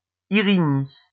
Irigny; французское произношение: [iʁiɲi] (Звук